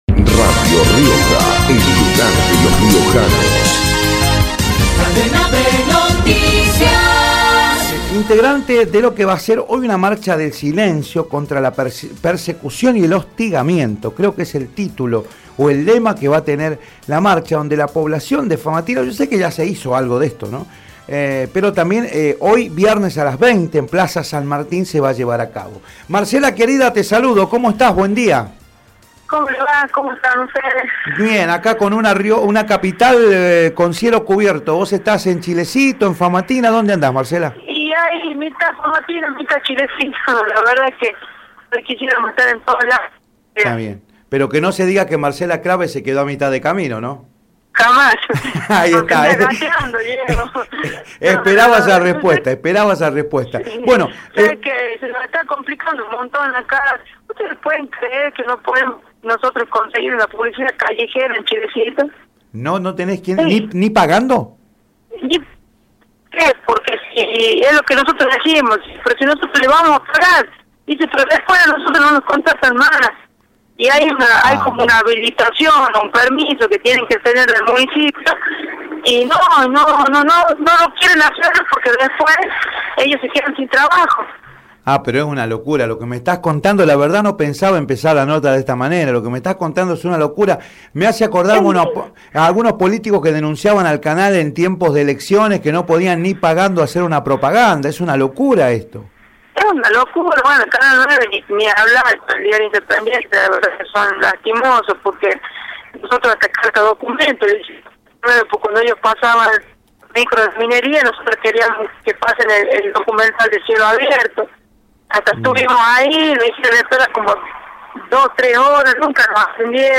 asambleísta de Chilecito, por Radio Rioja